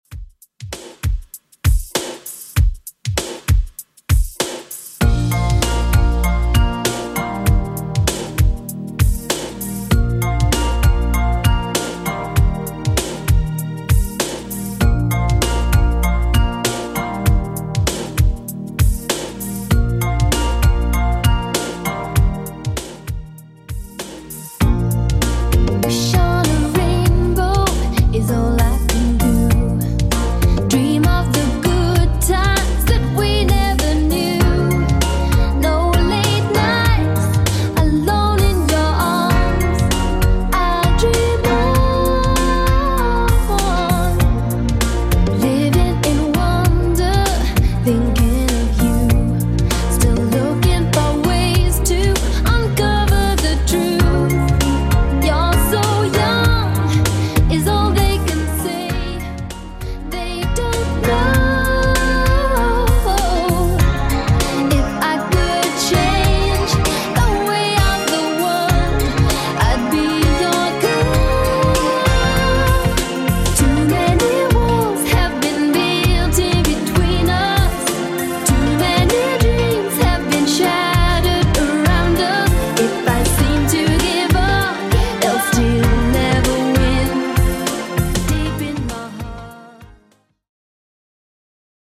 Genre: 90's
BPM: 105